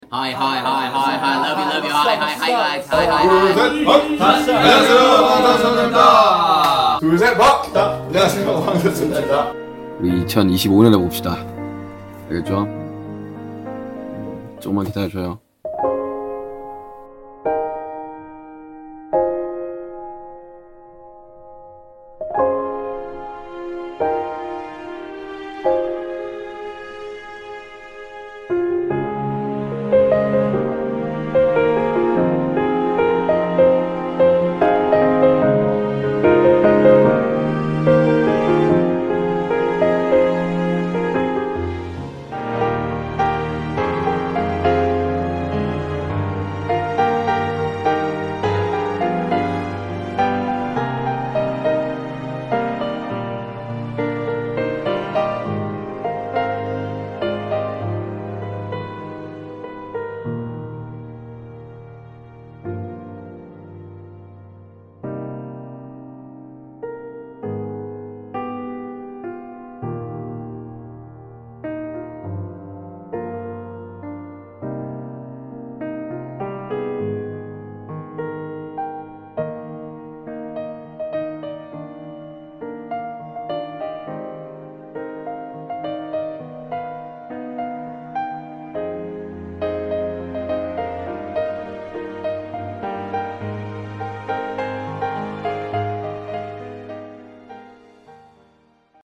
one global chorus, one unified melody
” eight songs